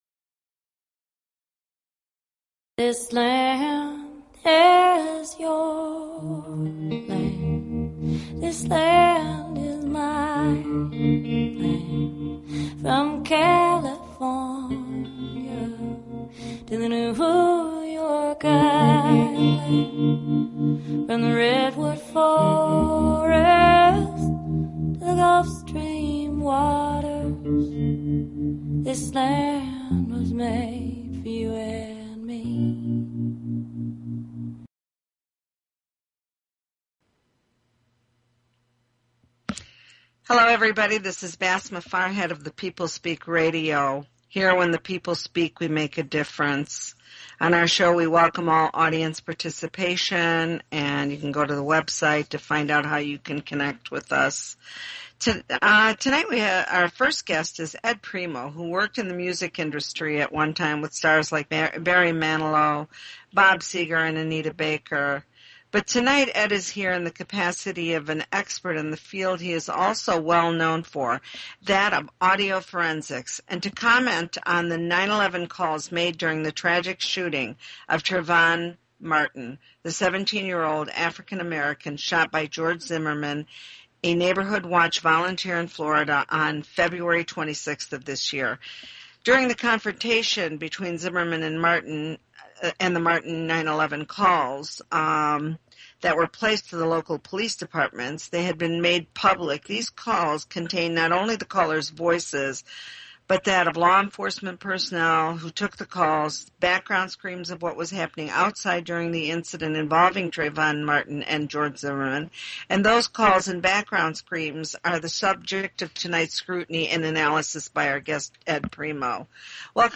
Talk Show Episode
Coincidentally, Roseanne had very outspoken ideas about the Trayvon Martin case and actually called in on the first part of the show to comment on the Trayvon Martin/George Zimmerman case. Truly a special show with participation from a nationwide audience.